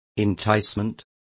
Complete with pronunciation of the translation of enticements.